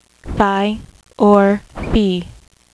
Phi (F-eye)